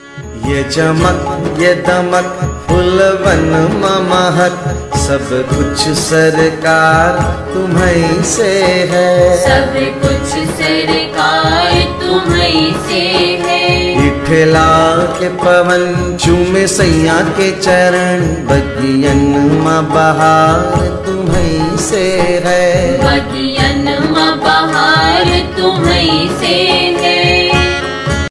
Bhajan Ringtones
Devotional Ringtones